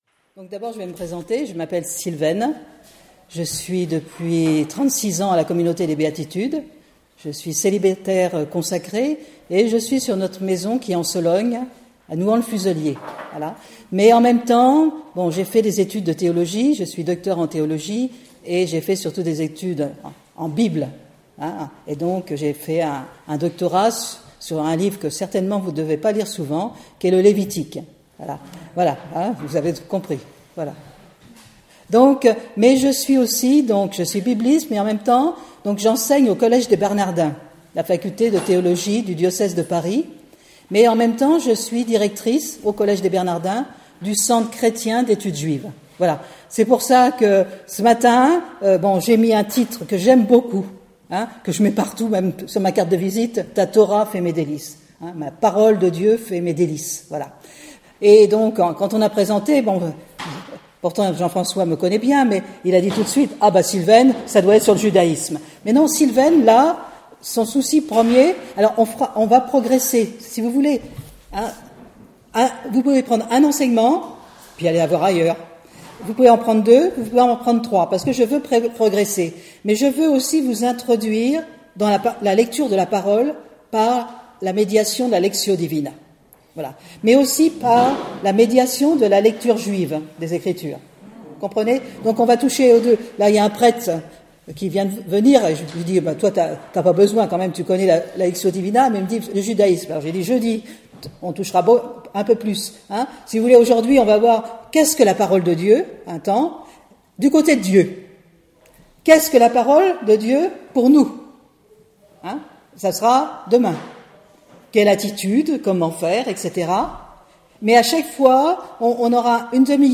Enseignement
Enregistré en 2017 (Session Béatitudes Lisieux)